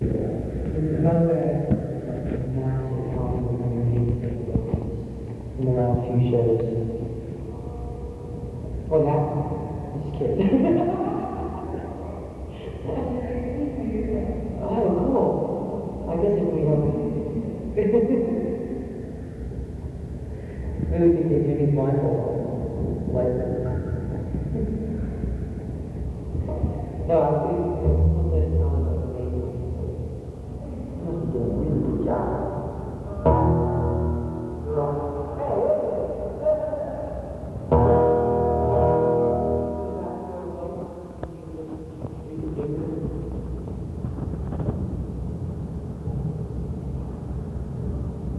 (unknown) (soundcheck) (0:48)